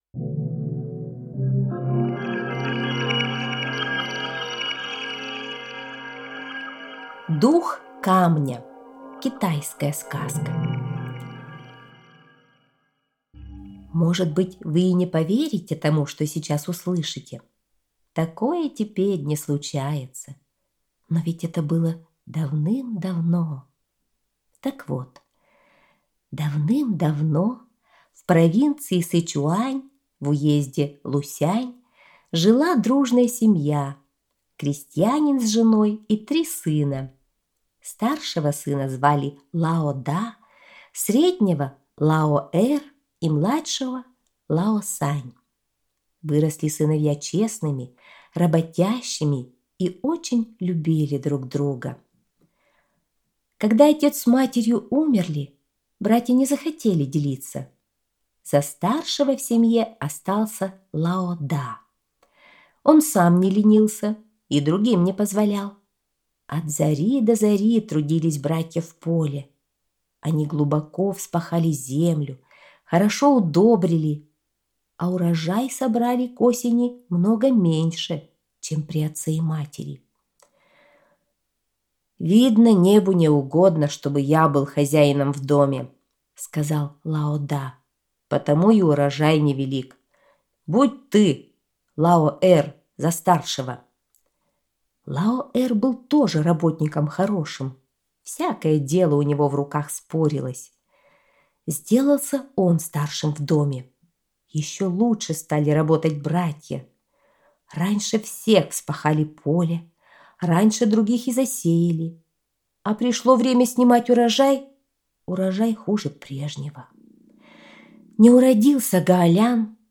Дух камня - китайская аудиосказка - слушать онлайн